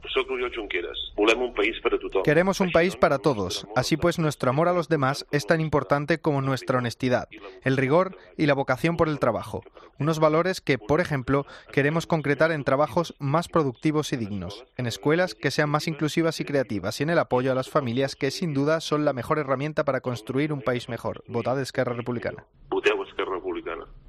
El candidato de ERC envía un audio desde la prisión de Estremera
El candidato de ERC, Oriol Junqueras, ha enviado un mensaje de audio desde la prisión de Estremera este sábado. Se trata del primer mensaje de voz de Junqueras desde que ingresó en la prisión  el pasado 2 de noviembre. Junqueras defiende que el futuro sólo tiene sentido si se trabaja para construir una sociedad justa "desde el respeto más escrupuloso a la dignidad de cada uno".